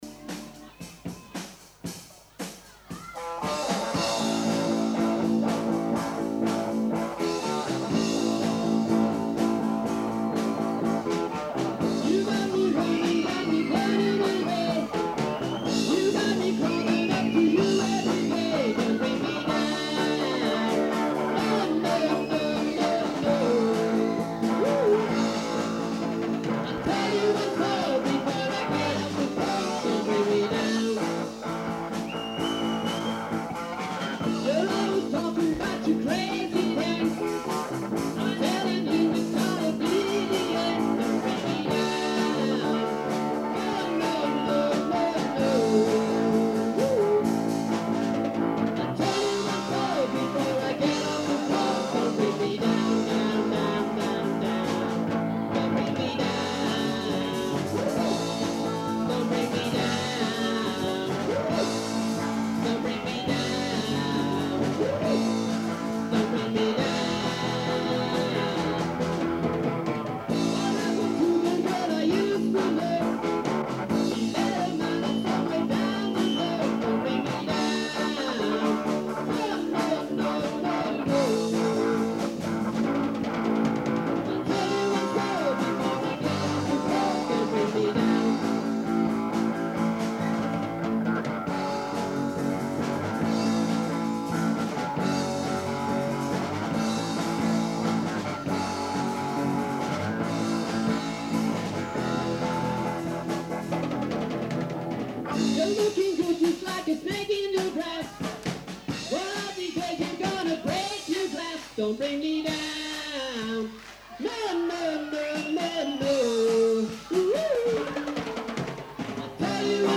Here are the recordings from two concerts performed at Horndean School.
The first is from July 12th 1983 in the assembly hall.